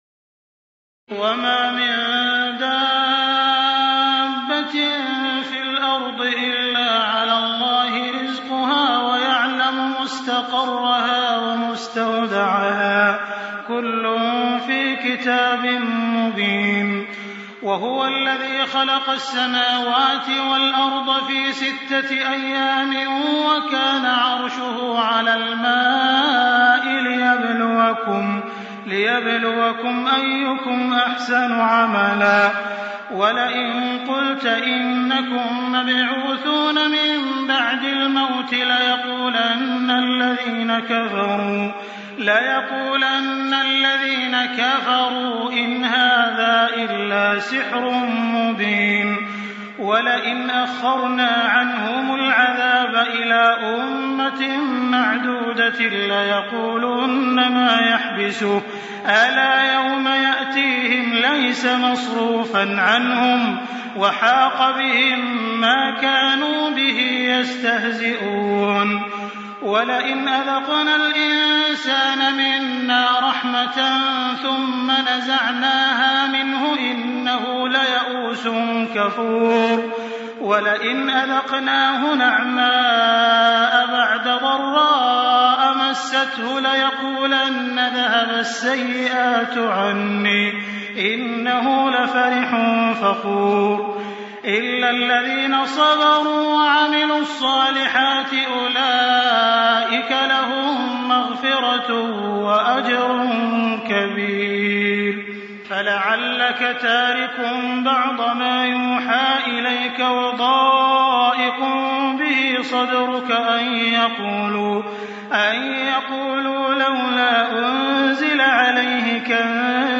تراويح الليلة الحادية عشر رمضان 1424هـ من سورة هود (6-83) Taraweeh 11 st night Ramadan 1424H from Surah Hud > تراويح الحرم المكي عام 1424 🕋 > التراويح - تلاوات الحرمين